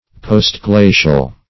Postglacial \Post*gla"cial\, a. (Geol.)